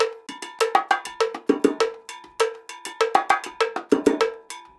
100 BONGO1.wav